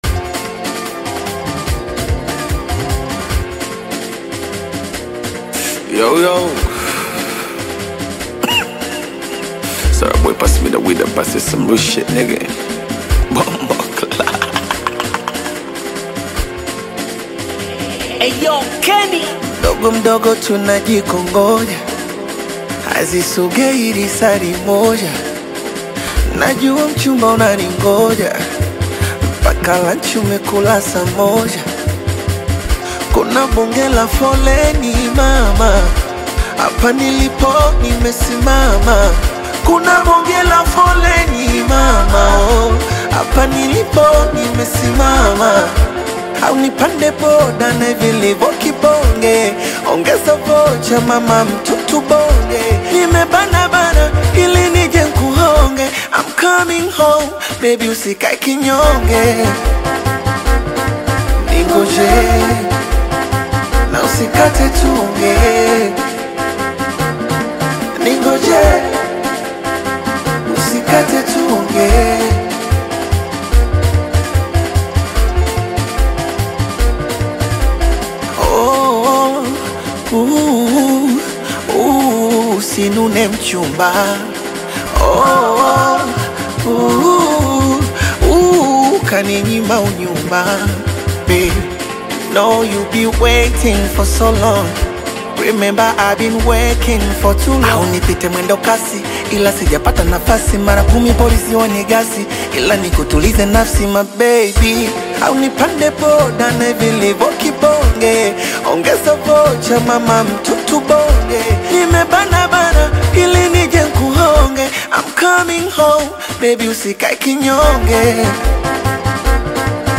fuses elements of Bongo Flava, Afrobeat, and R&B